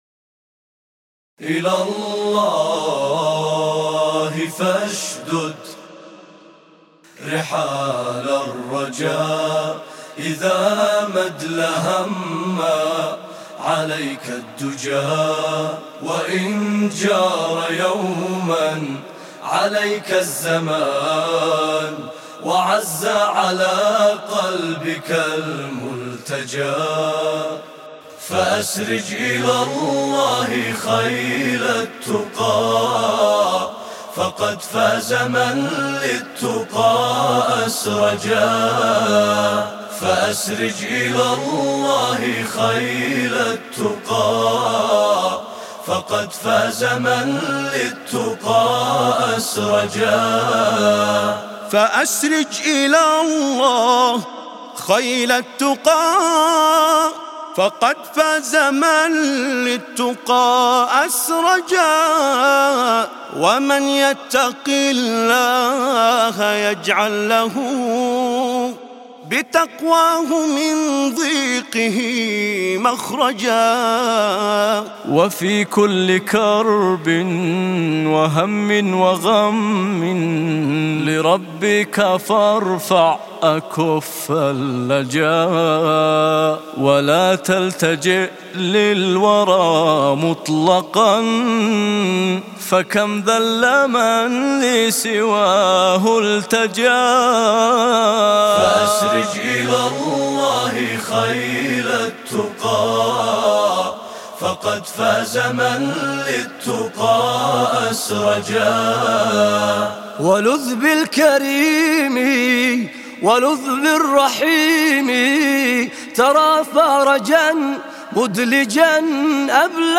mp3 بدون موسيقى